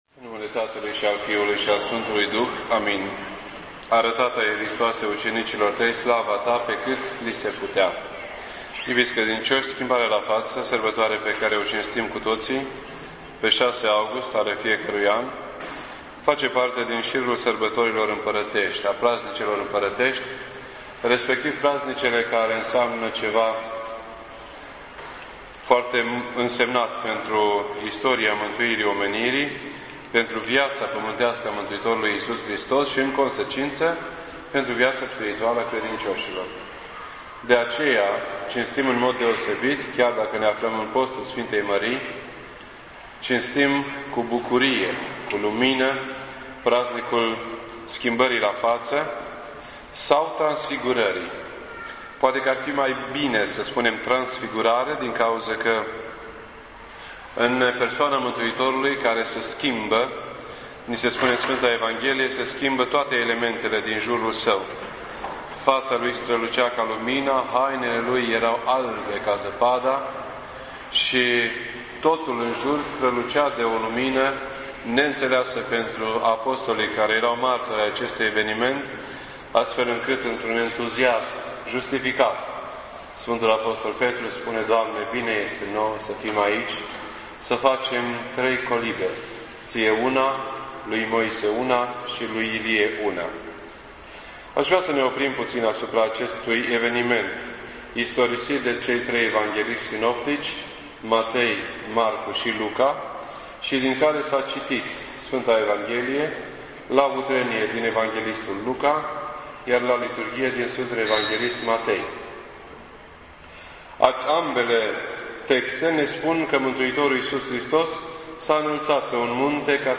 This entry was posted on Wednesday, August 6th, 2008 at 9:33 AM and is filed under Predici ortodoxe in format audio.